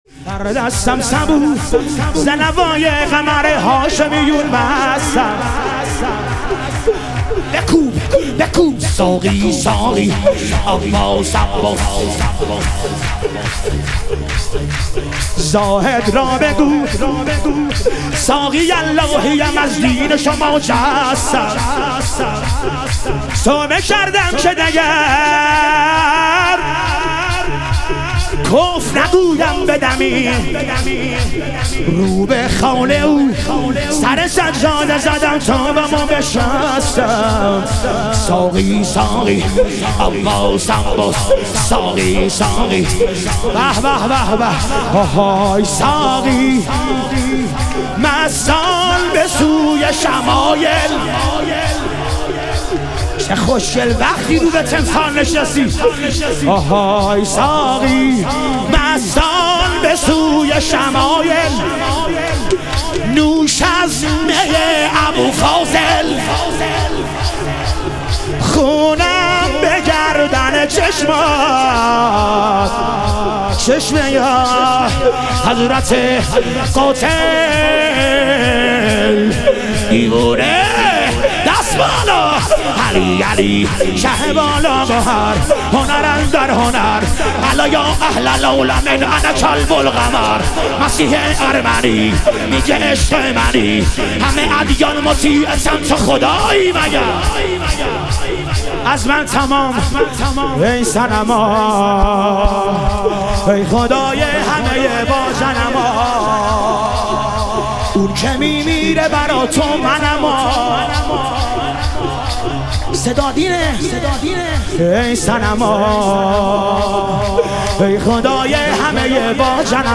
شور
شب ظهور وجود مقدس رسول اکرم و امام صادق علیهم السلام